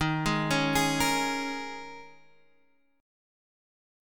Eb7sus4 chord